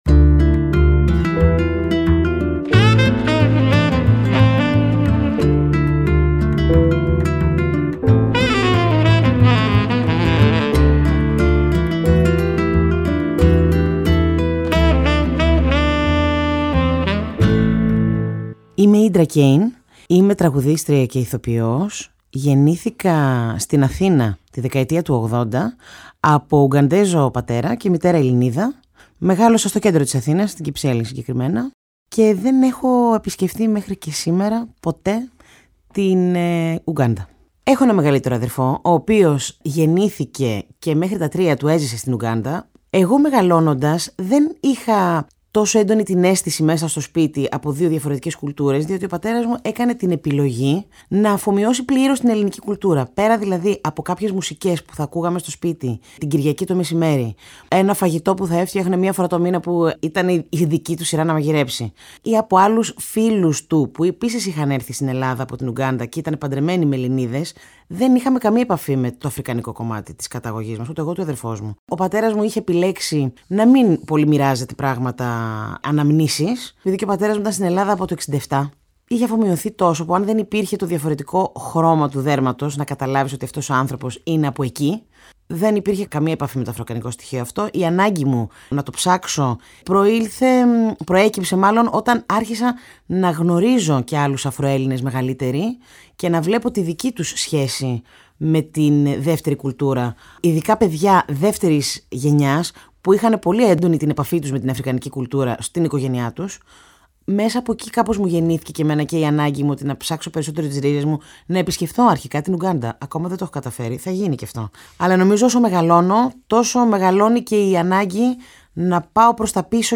Στο πλαίσιο της συνεργασίας του Μουσείου Μπενάκη με την ΕΡΤ και το Κosmos 93,6, ηχογραφήθηκε, ειδικά για την έκθεση, μια σειρά προσωπικών αφηγήσεων μερικών από τους μουσικούς που συμμετέχουν στο ηχοτοπίο ΗΧΗΤΙΚΕΣ ΔΙΑΣΤΑΣΕΙΣ ΑΦΡΙΚΑΝΙΚΗΣ ΔΙΑΣΠΟΡΑΣ.